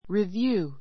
rivjúː